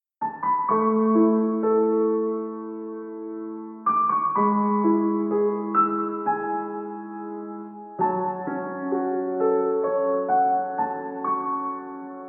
I lowered hammer noise, but that still does not sound soft as yours. Mine is too heavy. Please see the attachment, the first one is Valhalla Room, other is Valhalla Shimmer.